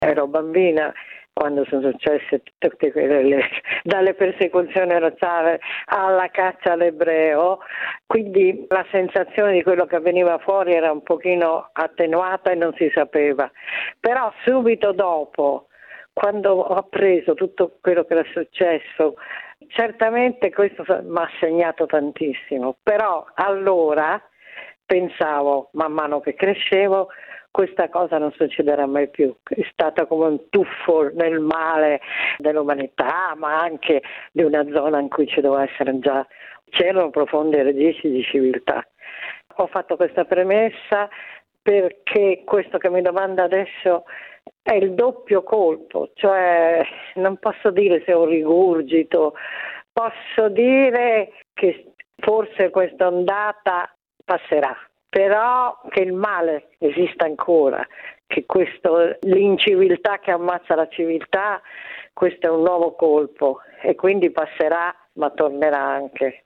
E il timore di un nuovo antisemitismo in Europa, una nuova ondata di odio contro gli ebrei, è concreto, come dice la scrittrice e giornalista Lia Levi che da piccola sfuggì alla deportazione degli ebrei del ghetto di Roma nascondendosi insieme alla madre in un convento della Capitale: